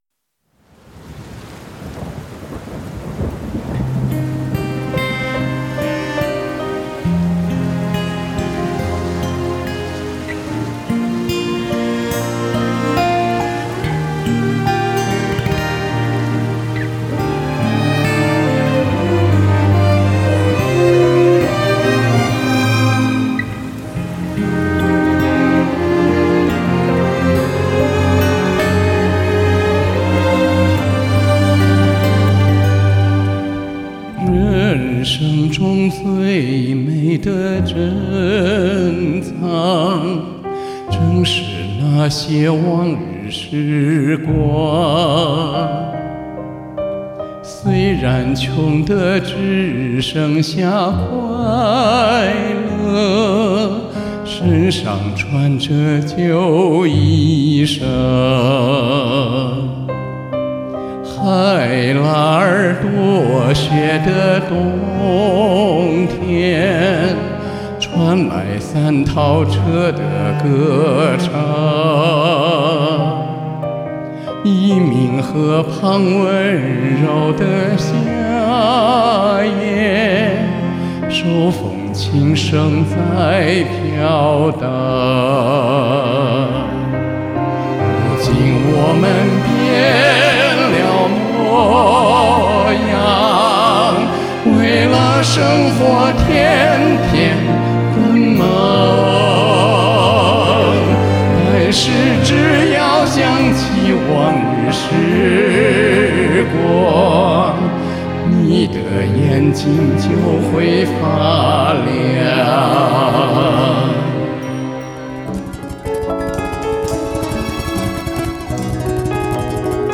大不了当替补的替补的替补：）开头的蒙古语就算了吧：）
和声从 如今我们变了模样开始。。。